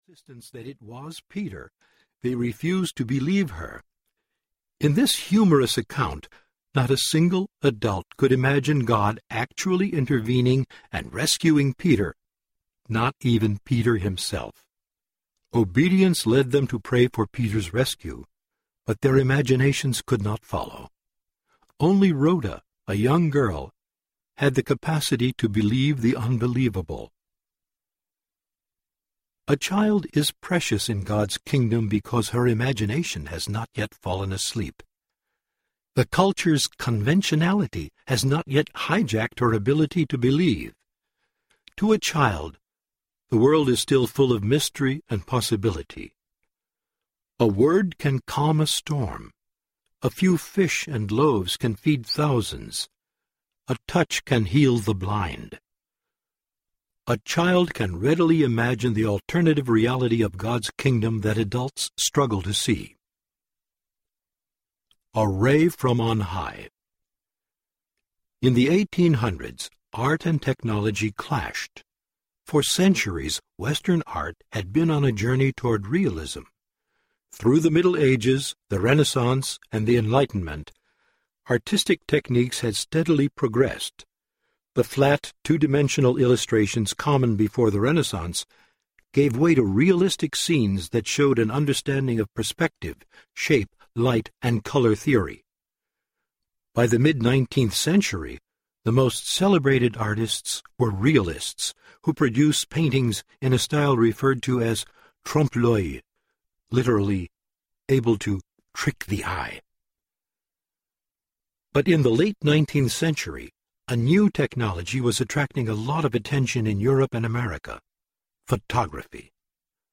The Divine Commodity Audiobook